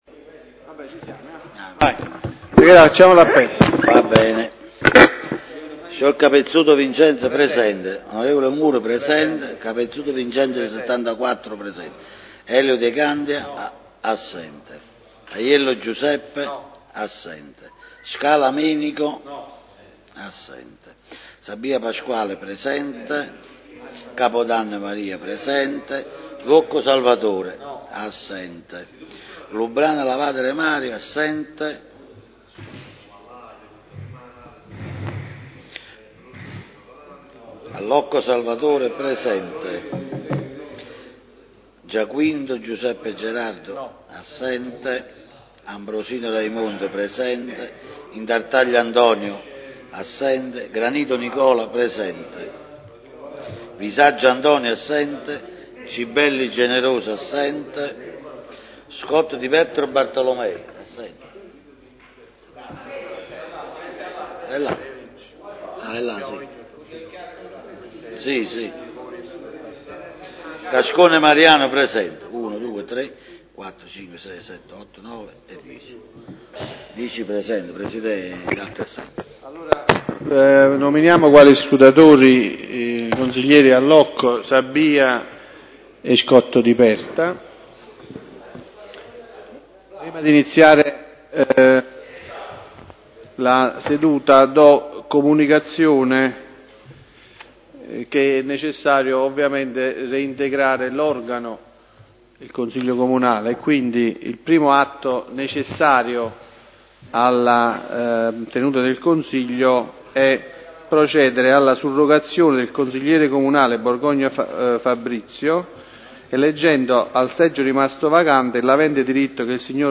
Pubblicato nella radio de Il Procidano la registrazione dell'ultimo Consiglio Comunale del 28 aprile 2015